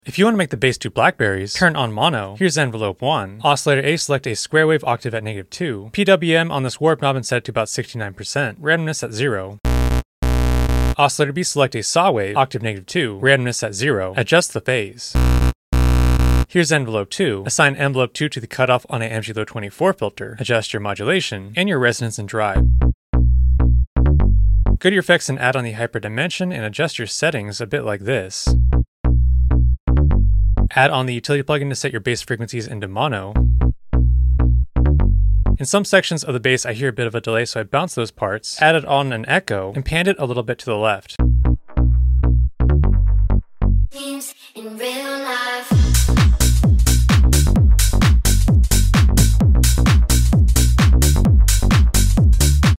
serum 2 tutorial